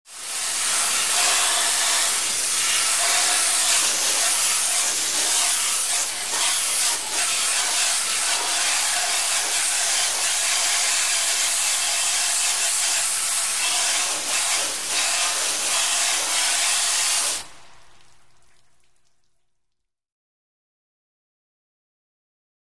Hose, Spray
Spraying Metal Car Grill In A Large Garage